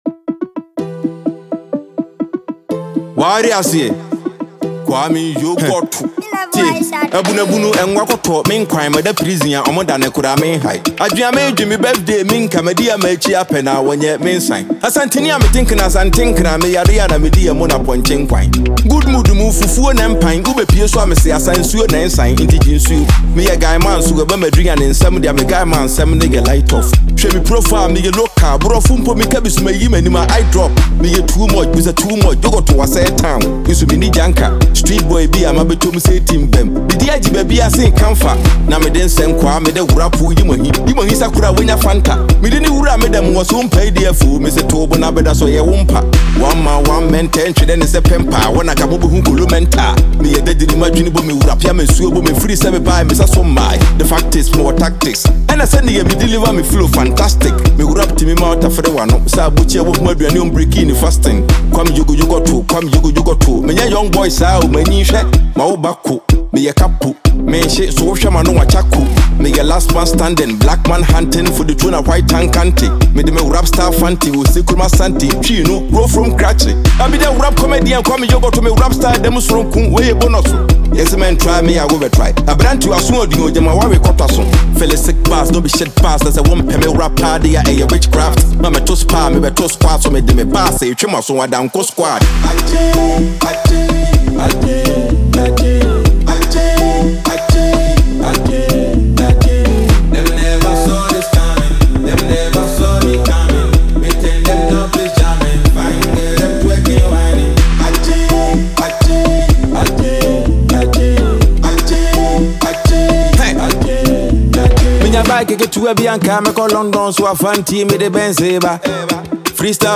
Ghana Music Music
The chorus stands out with its repetitive, fun nature